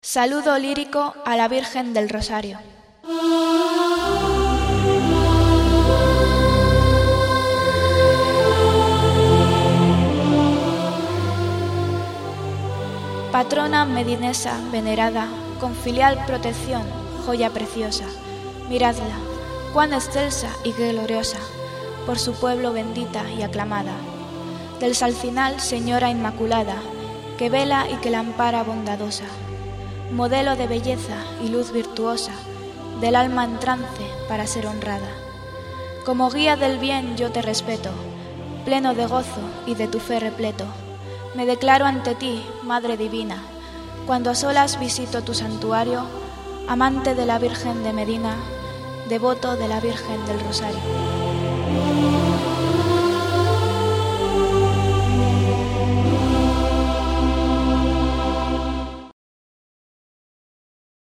Himno a la residencia del Rosario. Interpreta: El pueblo Canta.
HIMNO AL PIANO HIMNO